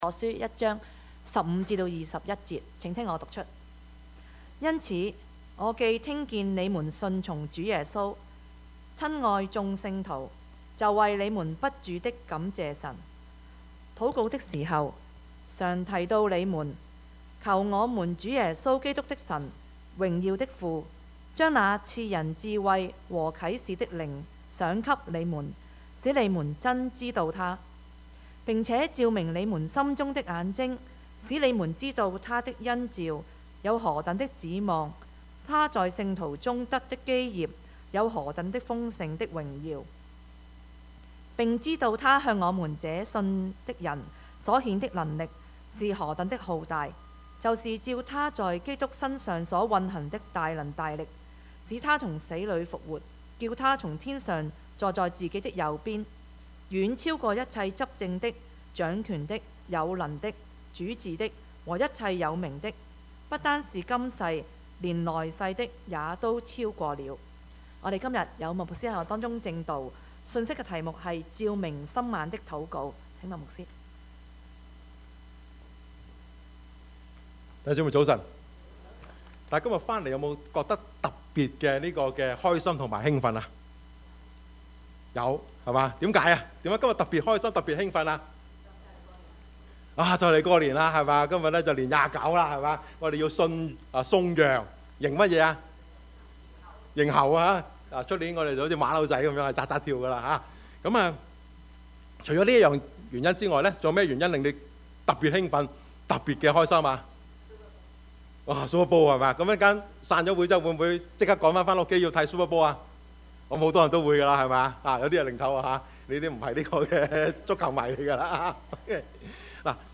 Service Type: 主日崇拜
Topics: 主日證道 « 高峰上的頌歌 今時唔同往日（上） »